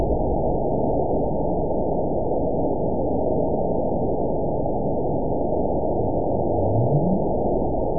event 920435 date 03/25/24 time 00:21:14 GMT (1 month ago) score 9.65 location TSS-AB02 detected by nrw target species NRW annotations +NRW Spectrogram: Frequency (kHz) vs. Time (s) audio not available .wav